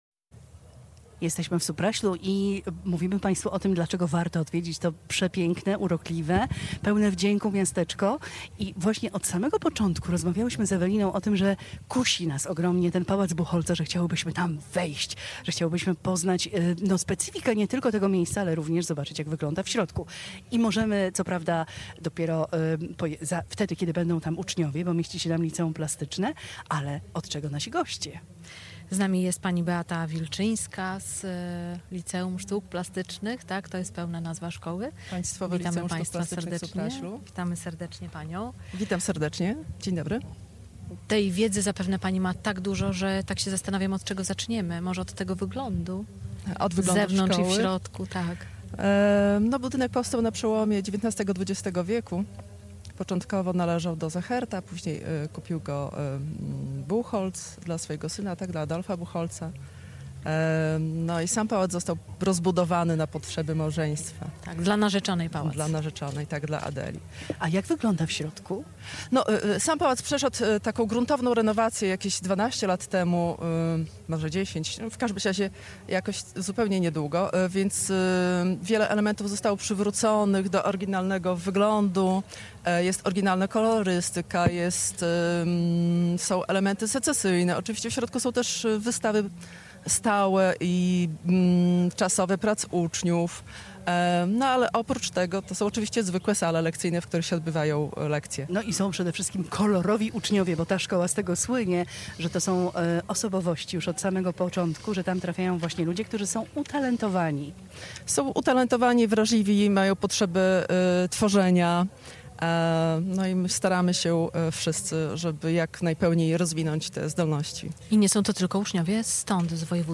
Rozmowa
Z naszego plenerowego studia ustawionego przed Pałacem Buchholtzów zachęcaliśmy do spacerowania po miasteczku i relaksu w okolicznych lasach.